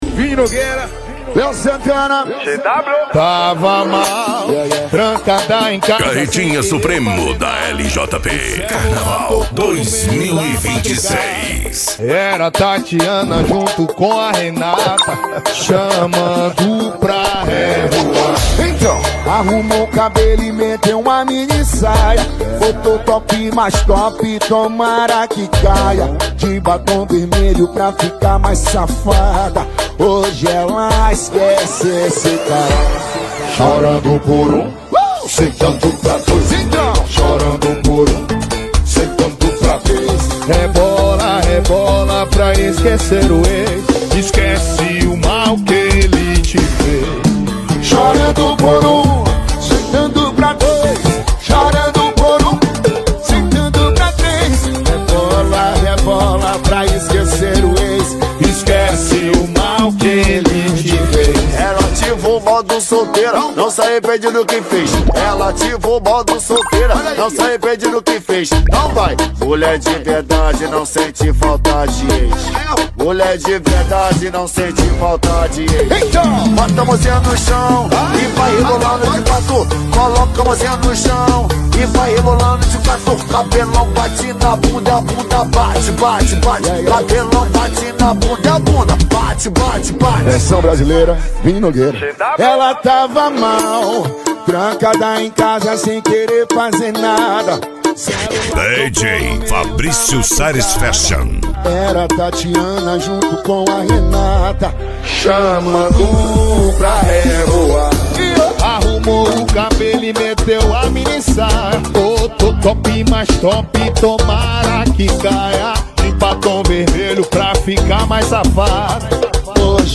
Arrocha
Funk